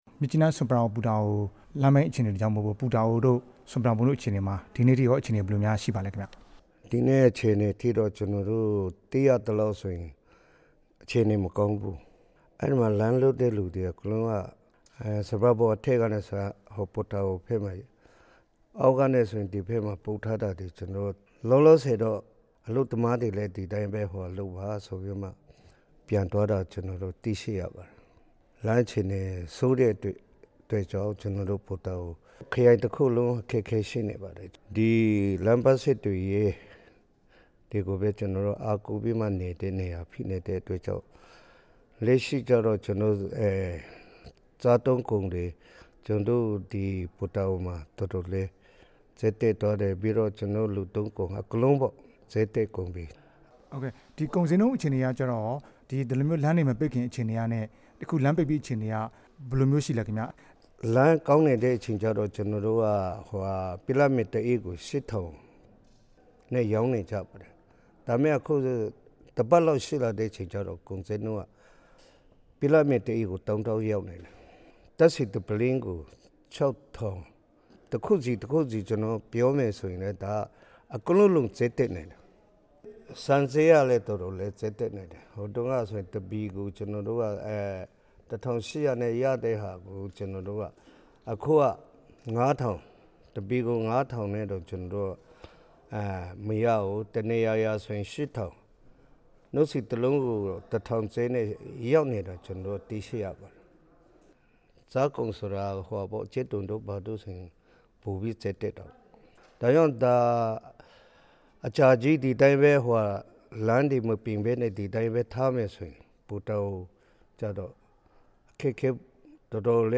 လမ်းတံတားဖျက်ဆီးမှု ကချင်လွှတ်တော်မှာ အရေးကြီးအဆို တင်မယ့်အကြောင်း မေးမြန်းချက်